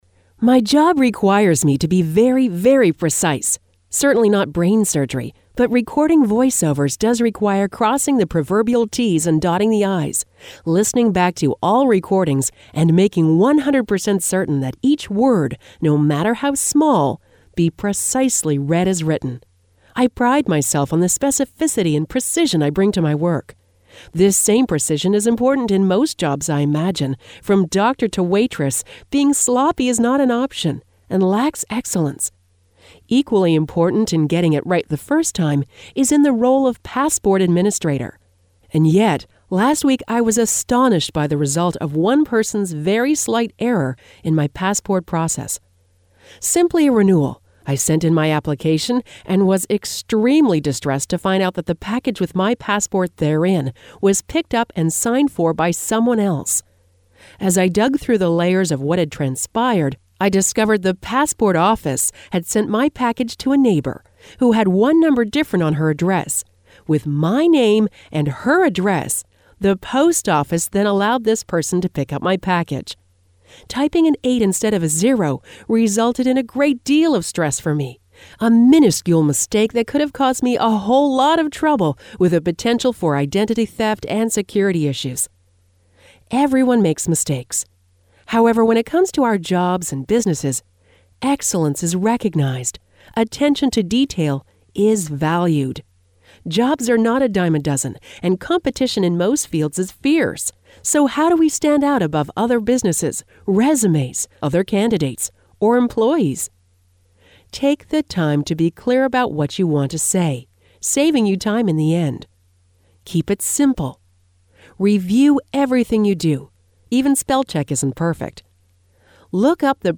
audio-blog-everyone-makes-mistakes.mp3